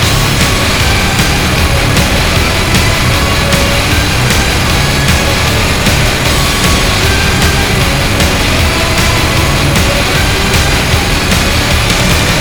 minigun_shoot.wav